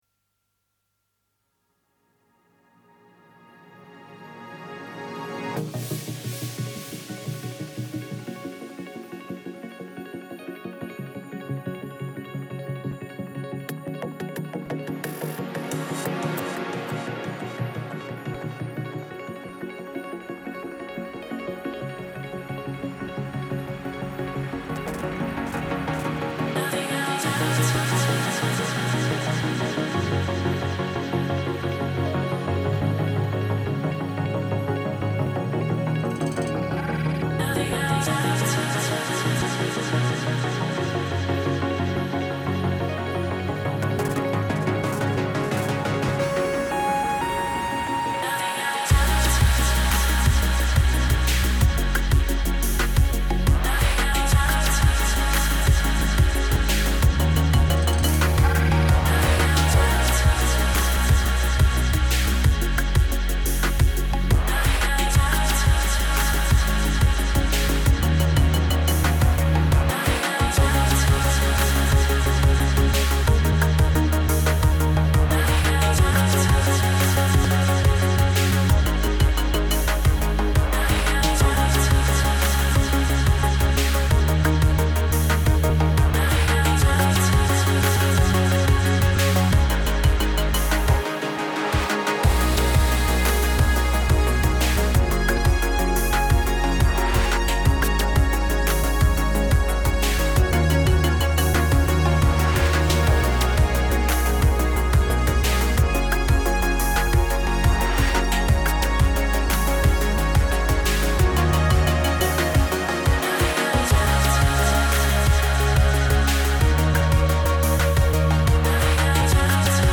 Getting in one last mix for 2010 and it is a belter.